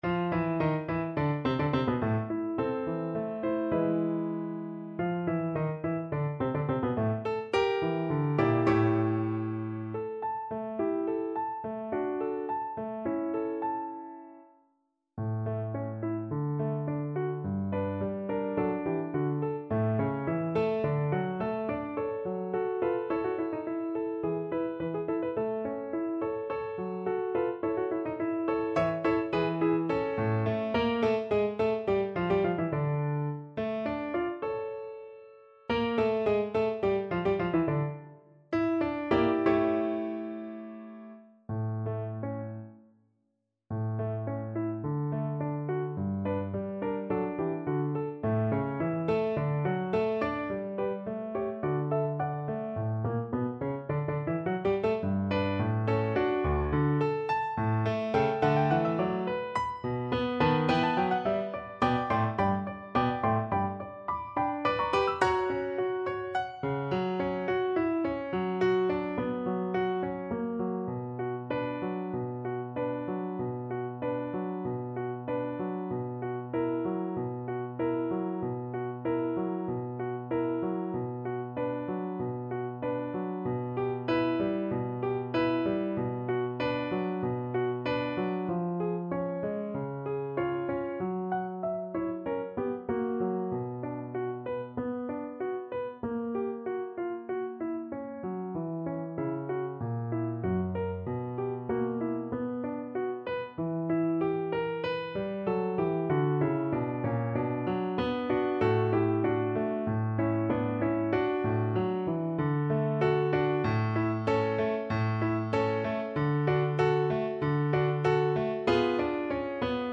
Allegretto =106
2/4 (View more 2/4 Music)
Classical (View more Classical Violin Music)